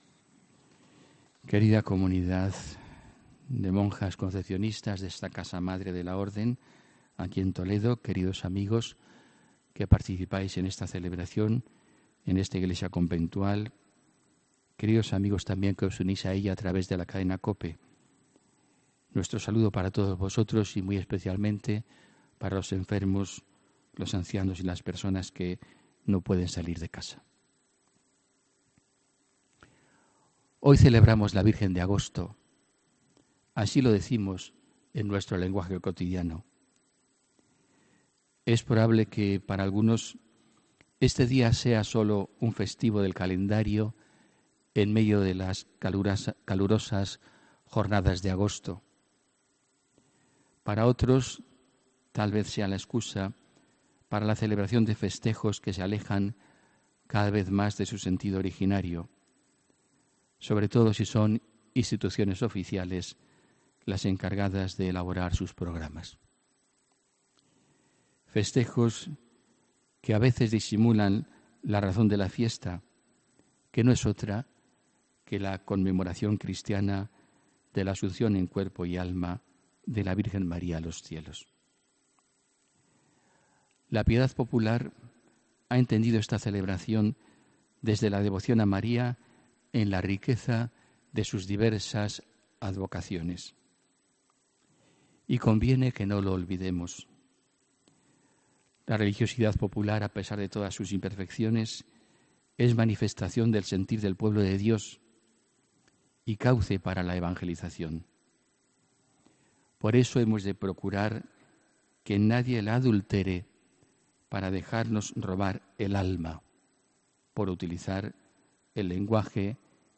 HOMILÍA 15 AGOSTO 2018